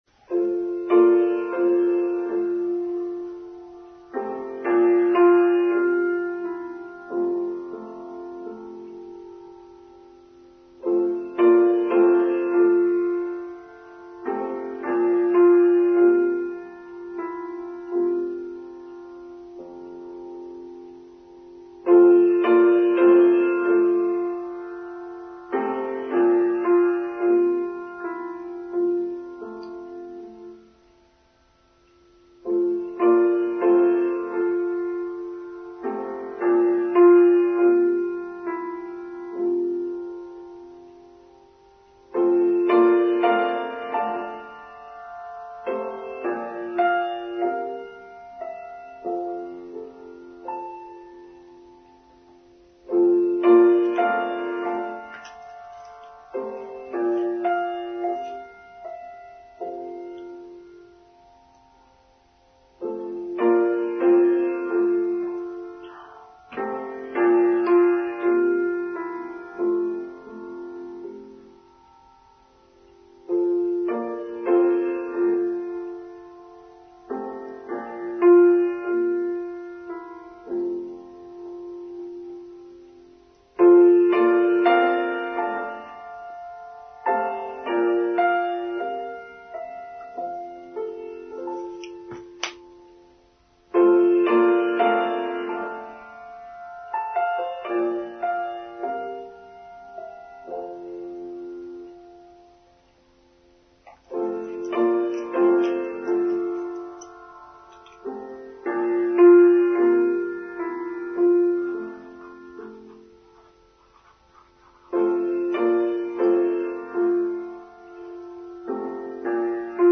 The Gift of Service: Online Service for Sunday 22nd October 2023
giftofservicetrimmed.mp3